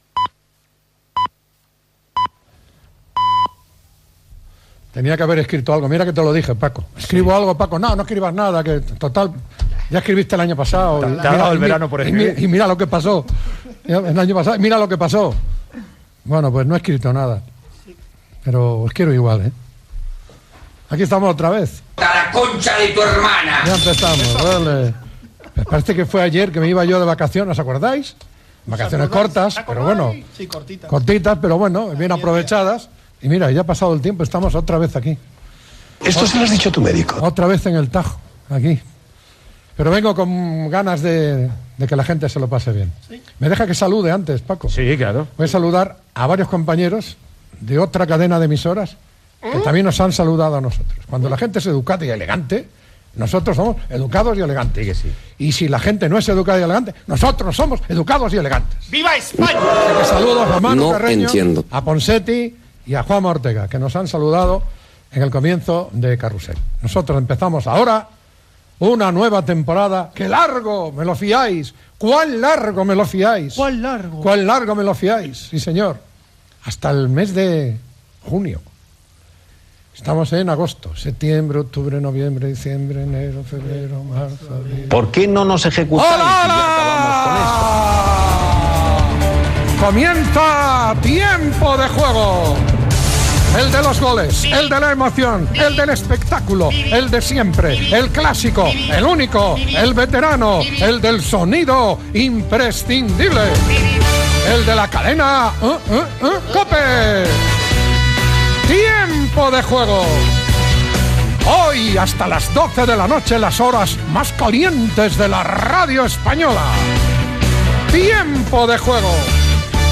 Salutació i comentaris inicials.
Publicitat, invitació a participar al programa i cançó del programa.